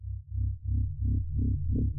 low_pulse_1.L.wav